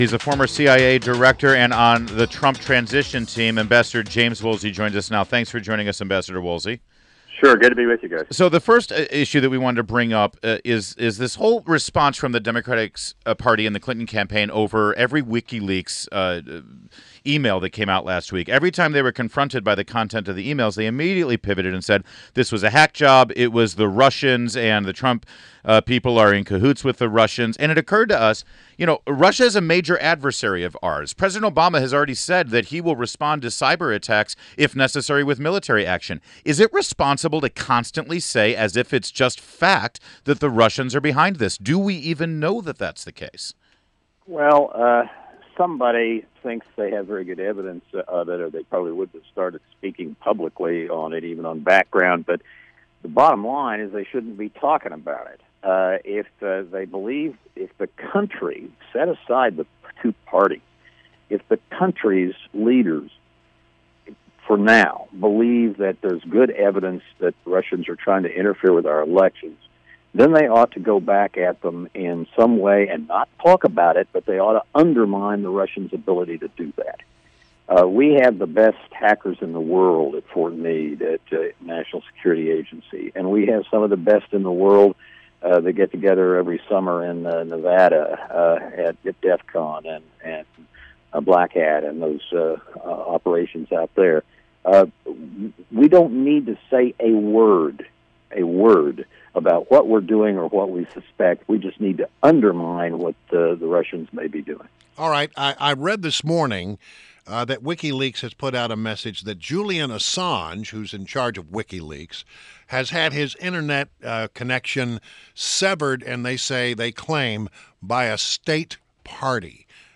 WMAL Interview - AMB. JAMES WOOLSEY - 10.17.16
INTERVIEW — AMB. JAMES WOOLSEY – former CIA director and is on Trump transition team